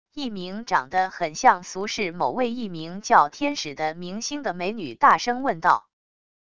一名长得很像俗世某位艺名叫天使的明星的美女大声问道wav音频生成系统WAV Audio Player